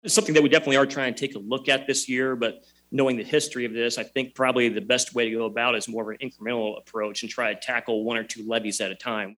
The legislators made their comments during a forum sponsored by the Iowa Taxpayers Association.